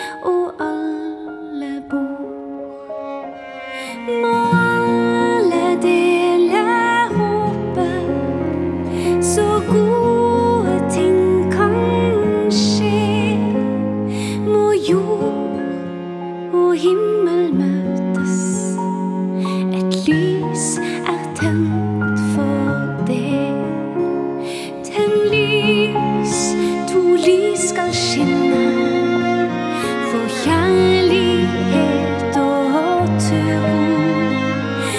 # Christmas: Religious